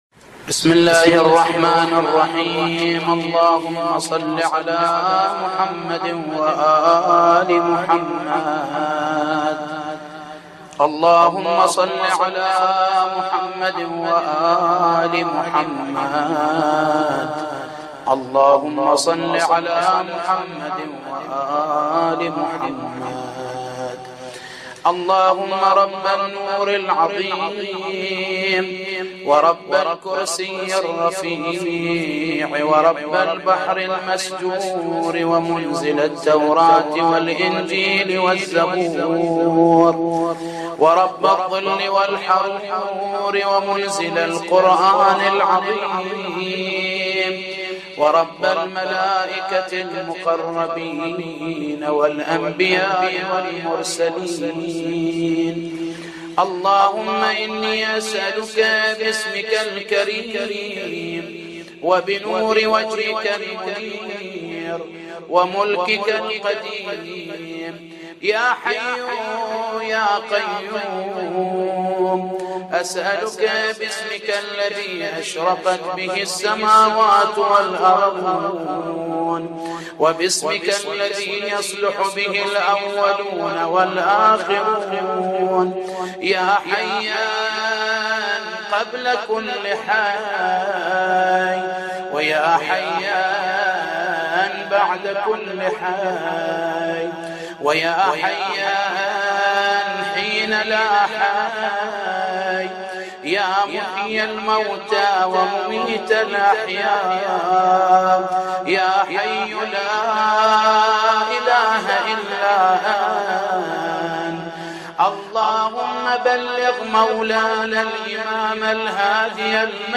• القسم الفرعي : أدعية .